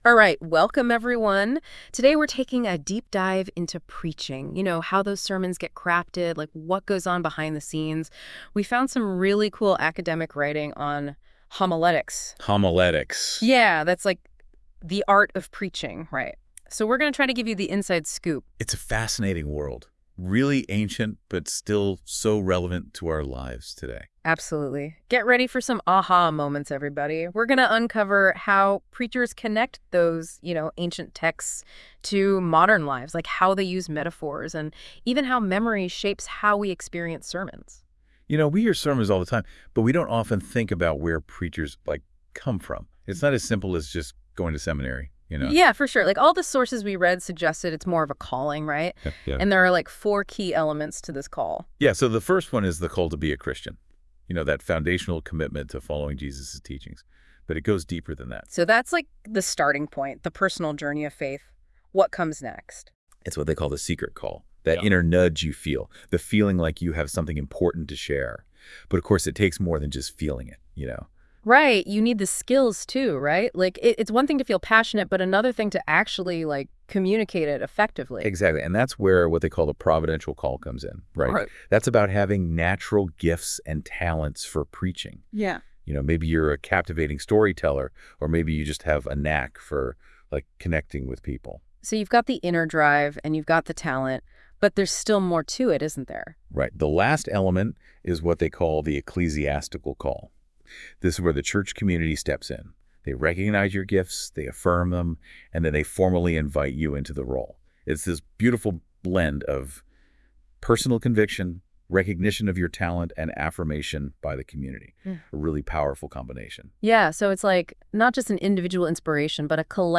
Also, AI generated a podcast using my book as the content.